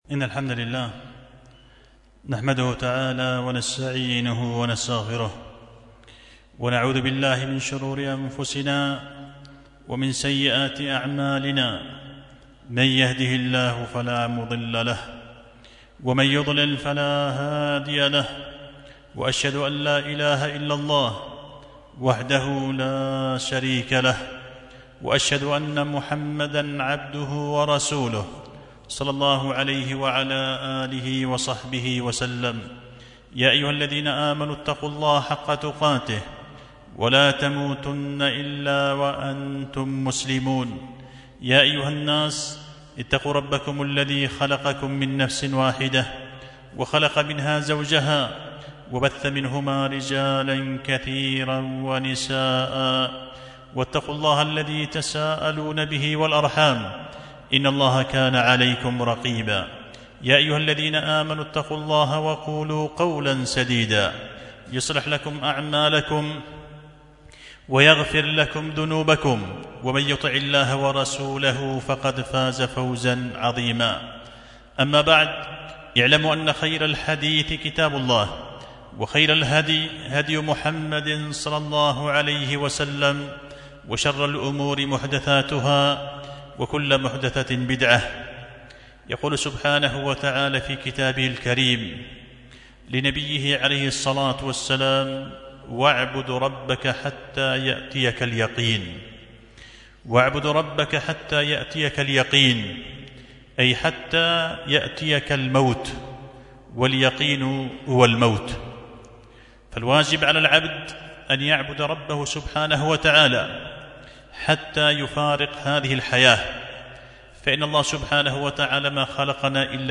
خطبة جمعة بعنوان نيل المكرمات عند الحرص على العبادة في أوقات وأماكن الغفلات